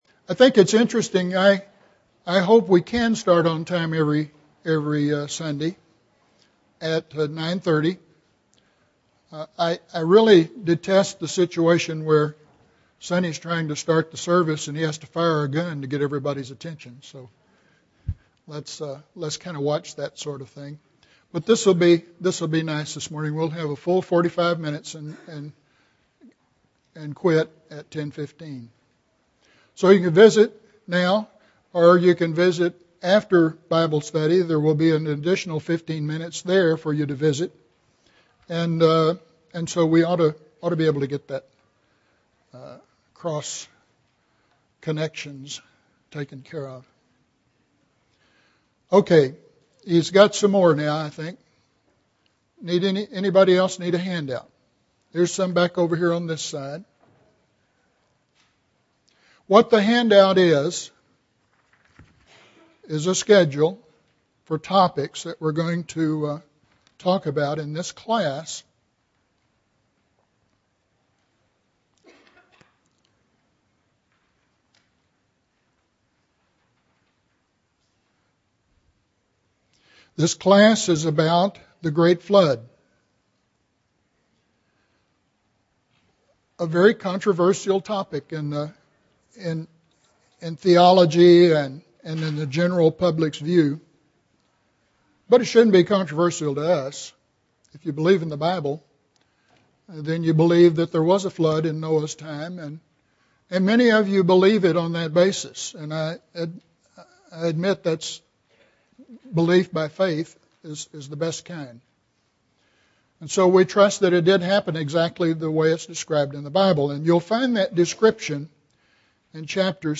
The Discovery of the Ark (1 of 10) – Bible Lesson Recording
Sunday AM Bible Class